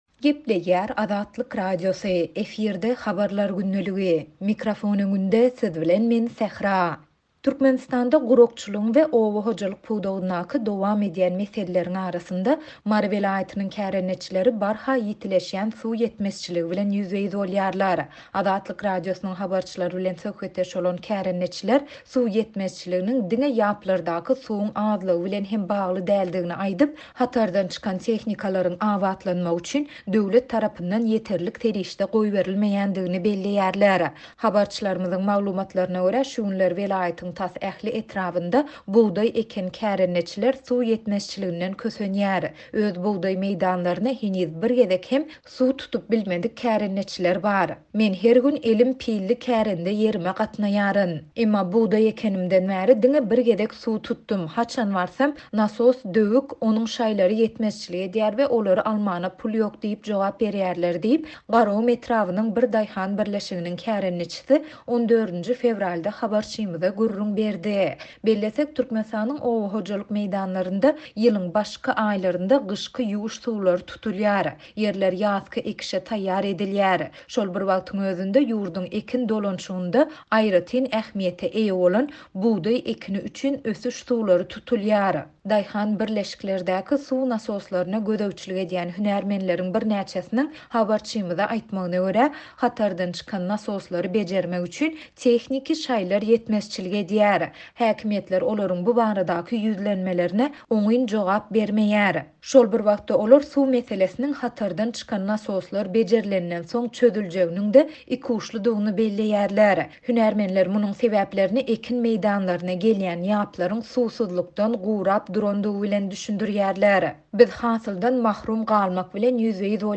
Türkmenistanda gurakçylygyň we oba hojalyk pudagyndaky dowam edýän meseleleriň arasynda Mary welaýatynyň kärendeçileri barha ýitileşýän suw ýetmezçiligi bilen ýüzbe-ýüz bolýarlar. Azatlyk Radiosynyň habarçylary bilen söhbetdeş bolan kärendeçiler suw ýetmezliginiň diňe ýaplardaky suwuň azlygy bilen bagly däldigini aýdyp, hatardan çykan tehnikalaryň abatlanmagy üçin döwlet tarapyndan ýeterlik serişde goýberilmeýändigini belleýärler.